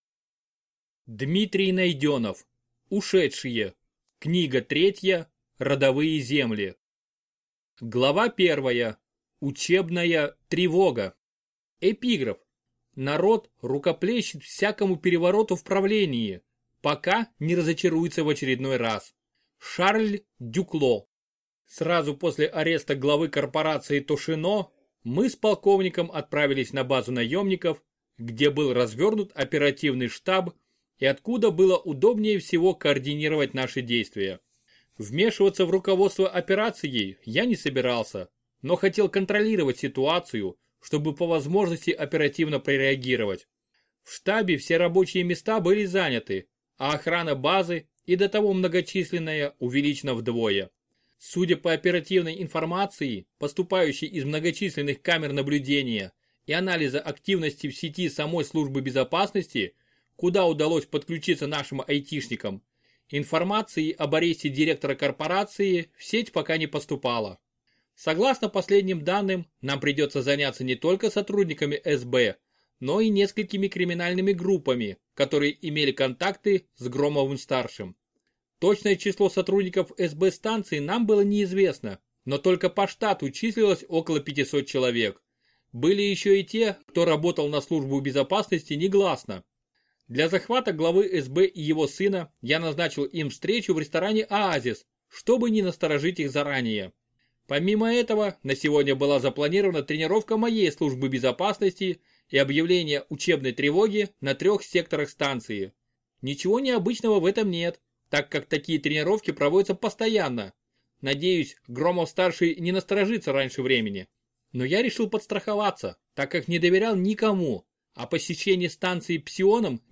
Аудиокнига Ушедшие. Родовые земли. Книга третья | Библиотека аудиокниг